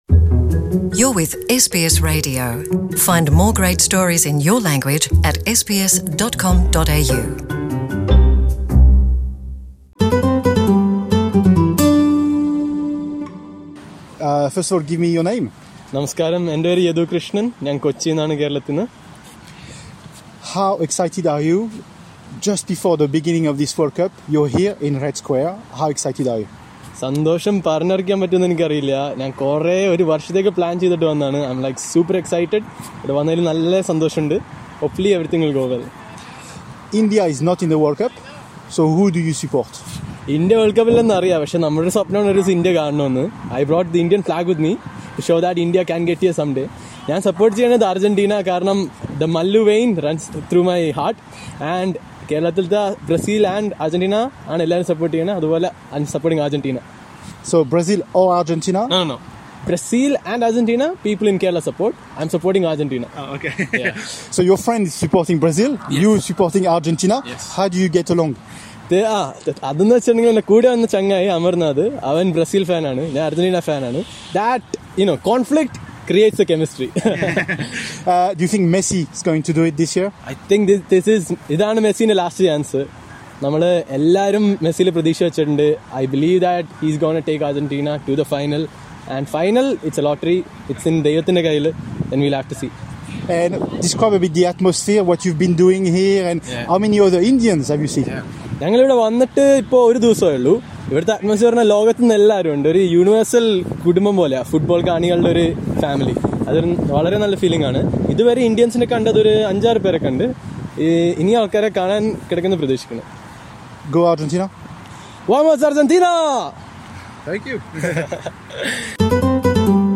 Listen to what the fans had to say ahead of the World Cup.